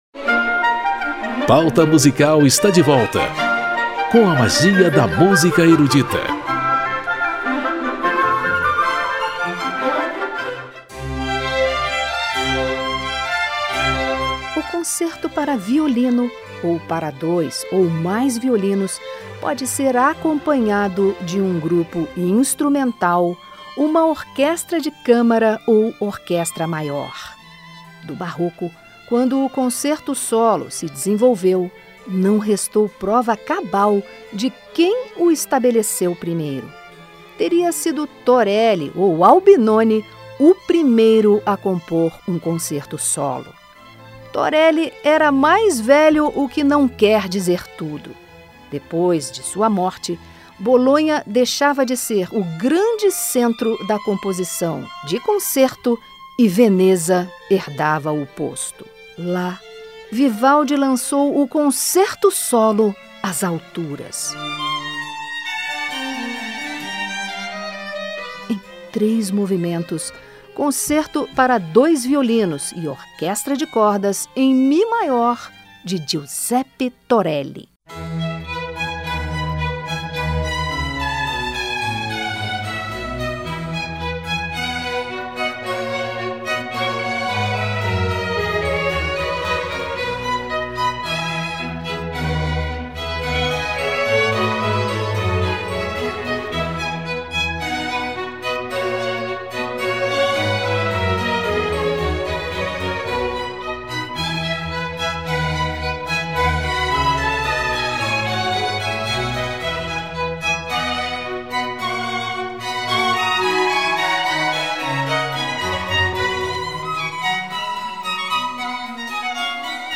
sonatas para cravo e 2 violoncelos
concertos para 2 violinos e orquestra